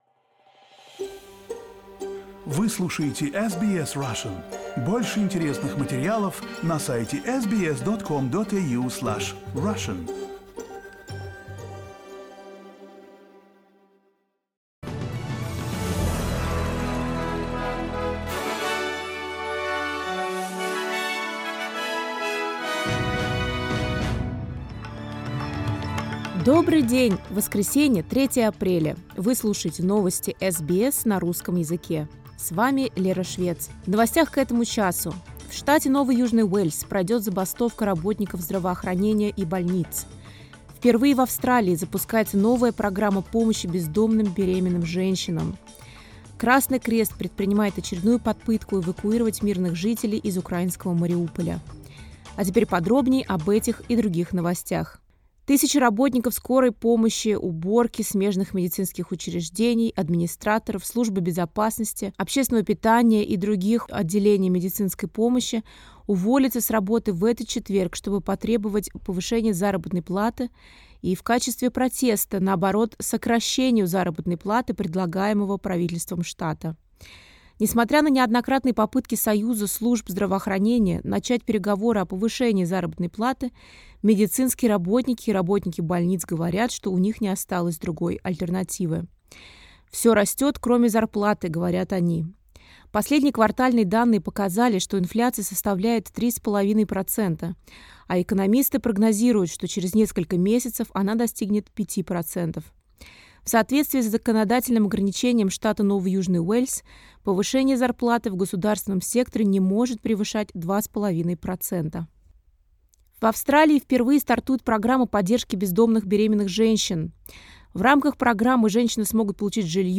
SBS news in Russian — 03.04